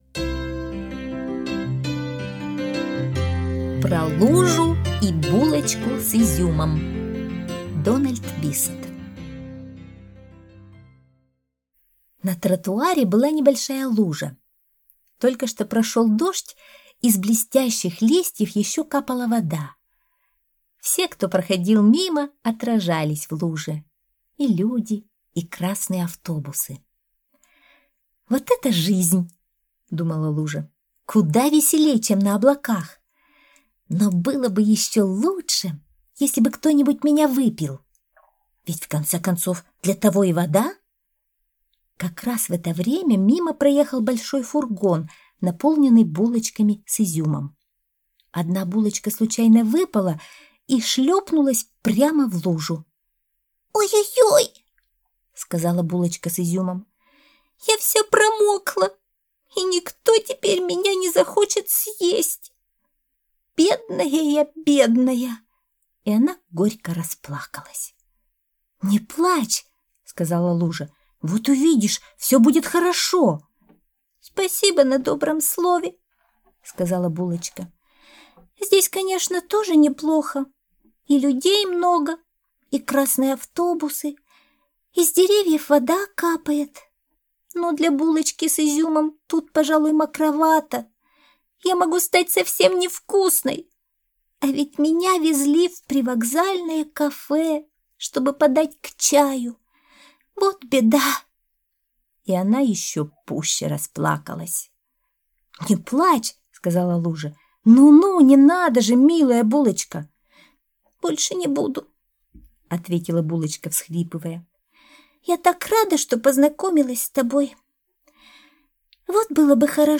Аудиосказка «Про лужу и булочку с изюмом»